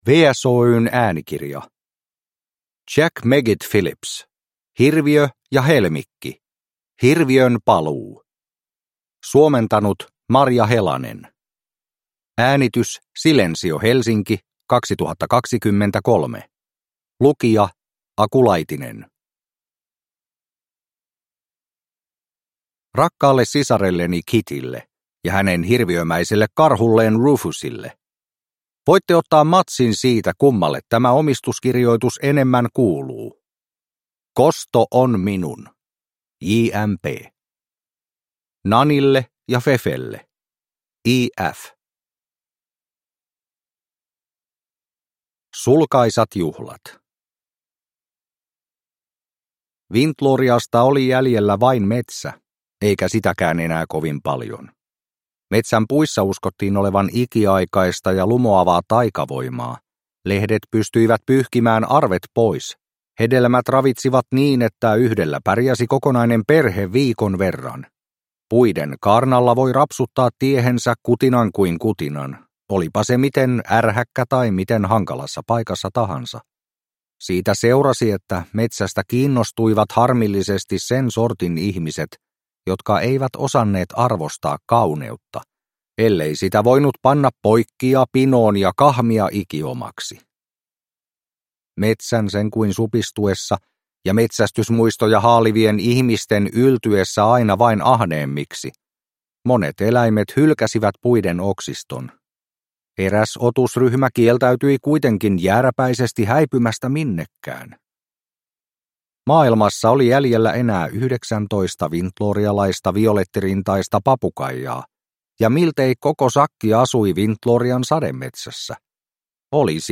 Hirviön paluu – Ljudbok – Laddas ner